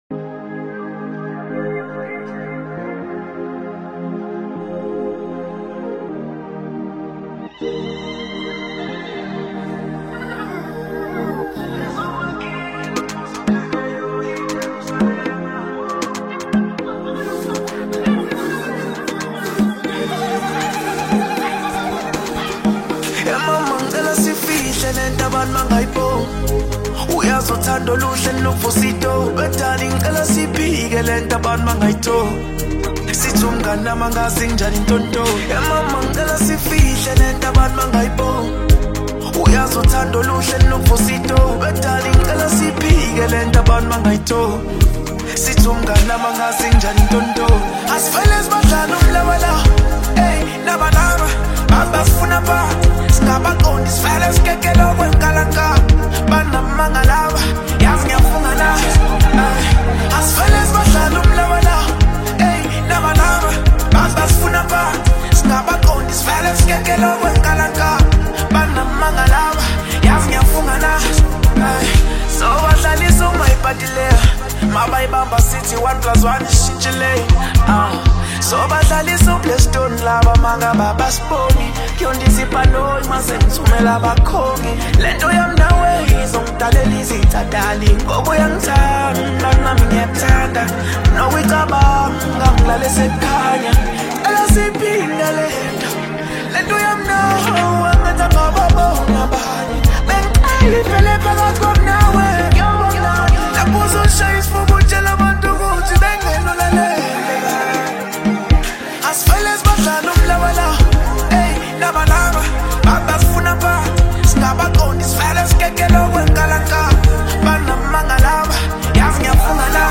seamlessly incorporates Afrobeat elements